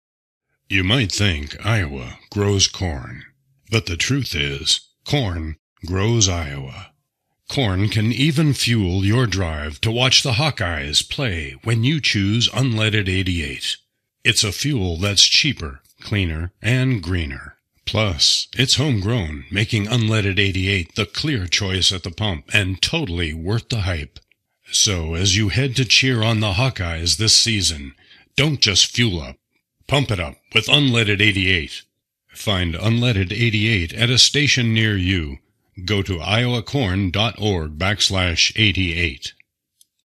Depending on the projects needs, I will record using a Sennheiser MKH416 or a TLM 103 mic in a sound studio with Avid Pro Tools Studio Edition.